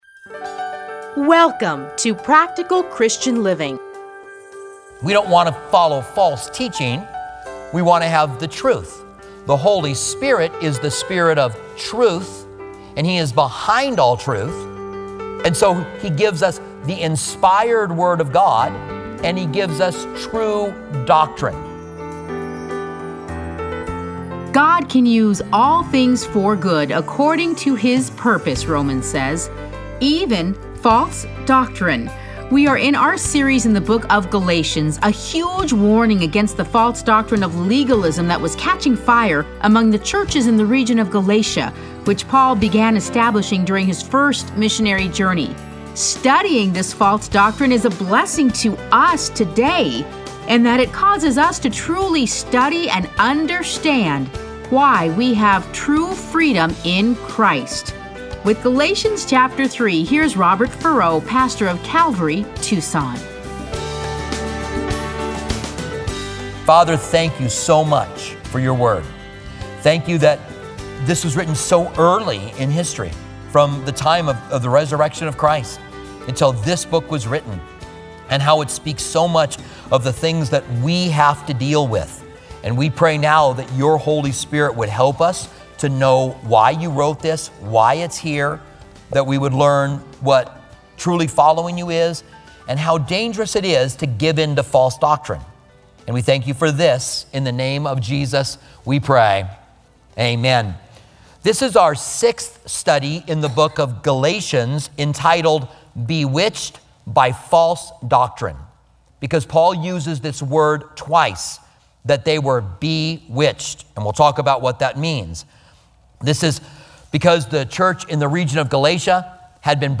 Listen to a teaching from Galatians 3:1-9.